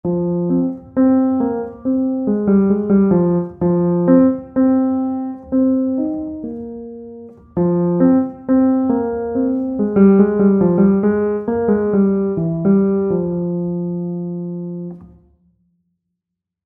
Fortepian
Na fortepianie gra się uderzając w klawisze, które pobudzają mechanizm młoteczkowy.
Dźwięki instrumentów są brzmieniem orientacyjnym, wygenerowanym w programach:
Fortepian.mp3